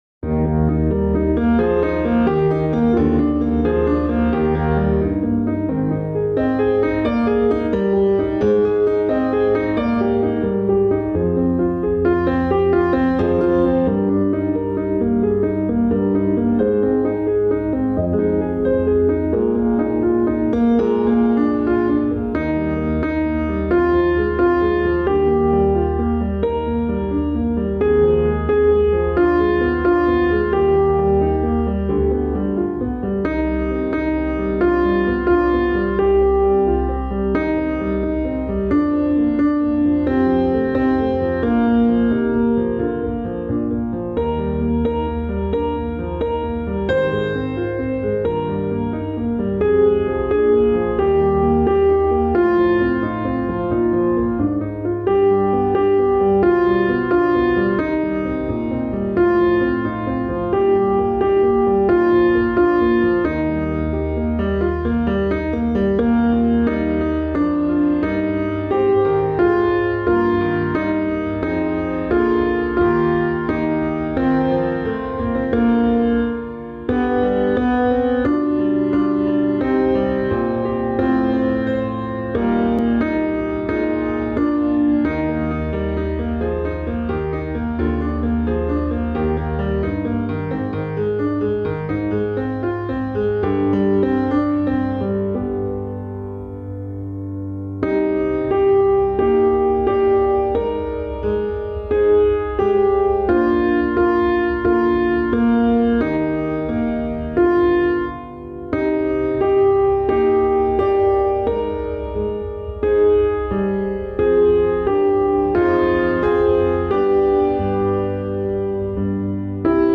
Like a River Glorious – Alto